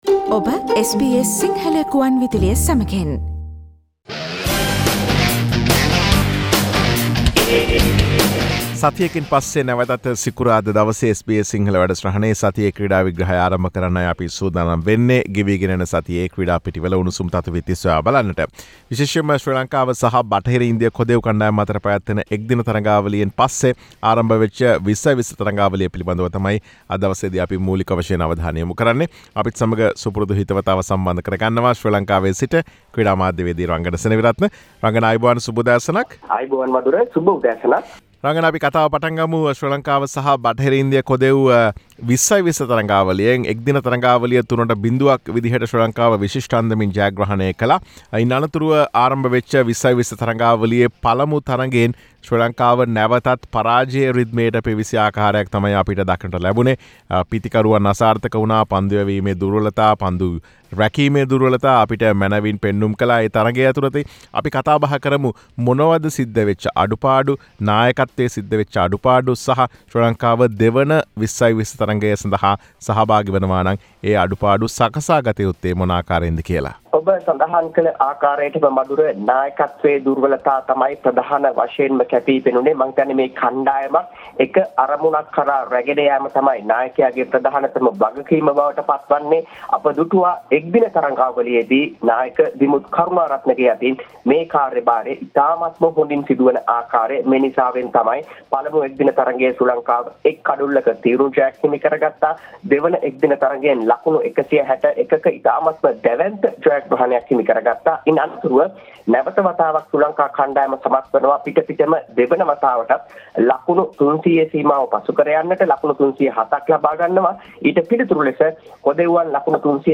SBS Sinhalese Sports Wrap